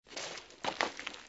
Toon_getting_up_guilt_trip.ogg